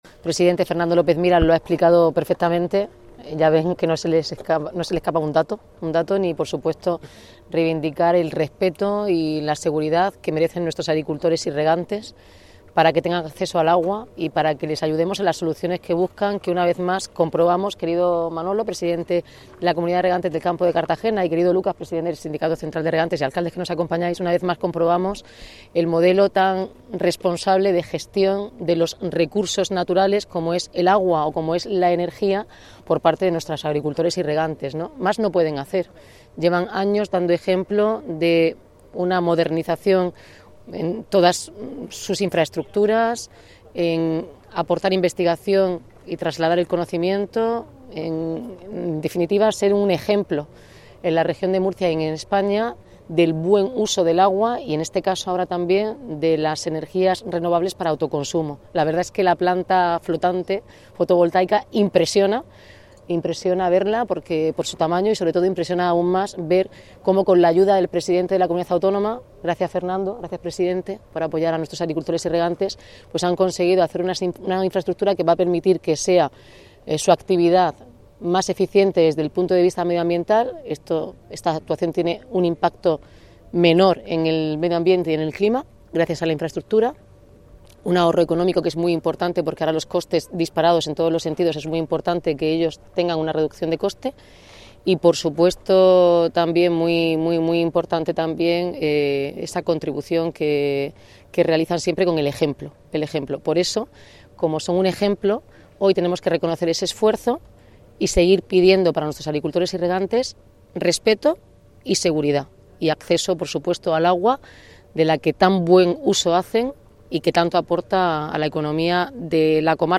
Cartagena refuerza su liderazgo en sostenibilidad y materia hídrica con la puesta en marcha de la nueva cubierta fotovoltaica flotante en el Embalse de Cola de la Comunidad de Regantes del Campo de Cartagena, que ha inaugurado este jueves 12 de marzo la alcaldesa de Cartagena, Noelia Arroyo, junto al presidente de la comunidad autónoma de la Región de Murcia, Fernando López Miras.